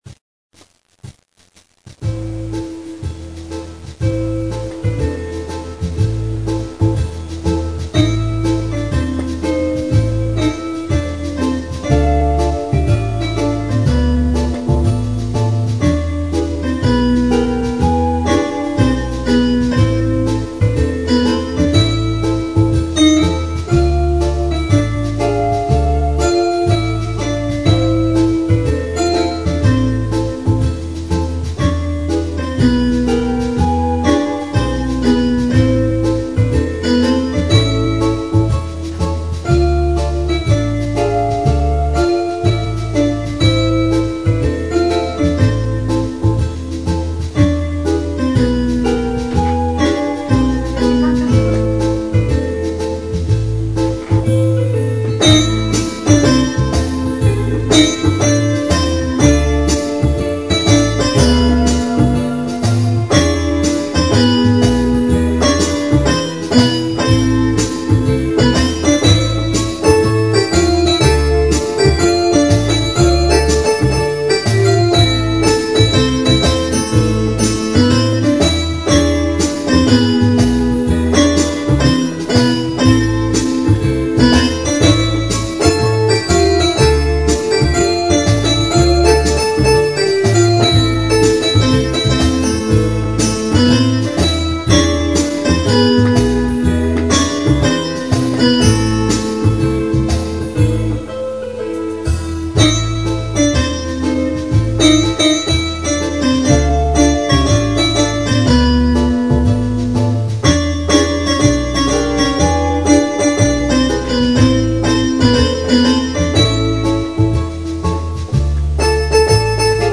Инструментальная музыка.
Романтическая музыка